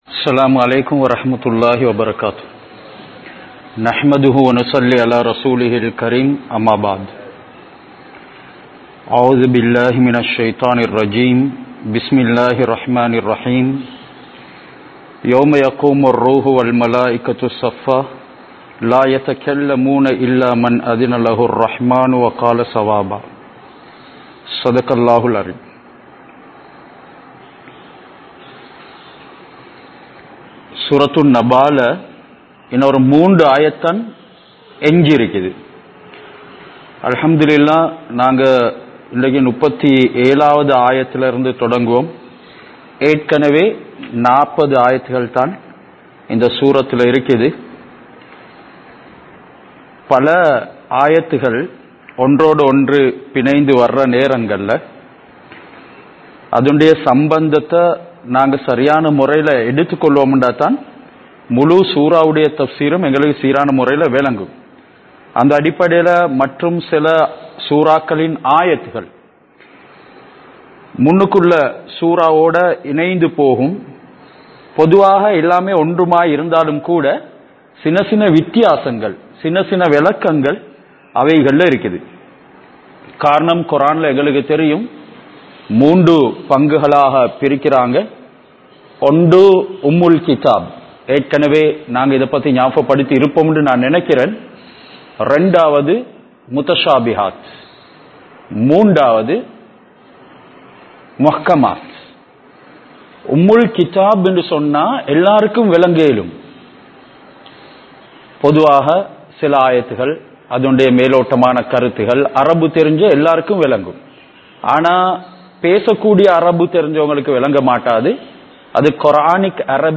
Surah An Naba (Thafseer Versus 37 - 40 ) | Audio Bayans | All Ceylon Muslim Youth Community | Addalaichenai